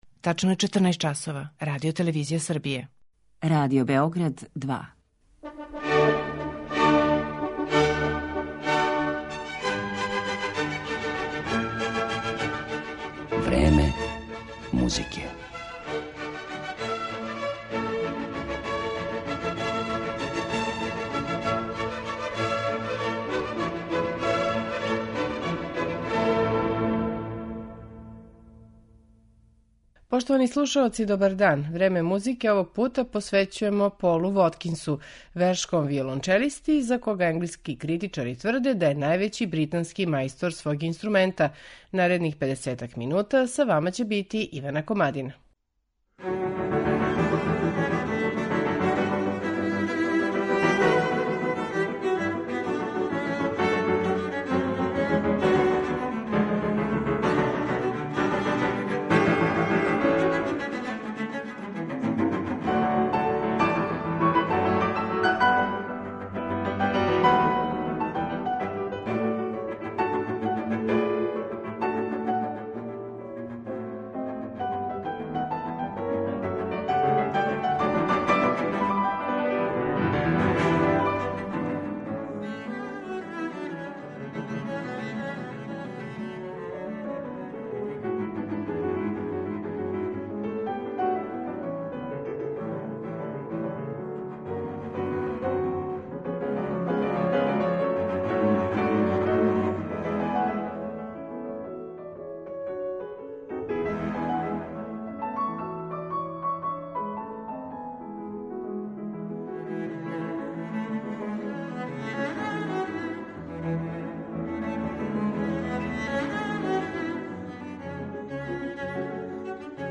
Данашњу емисију посветили смо британском виолончелисти Полу Воткинсу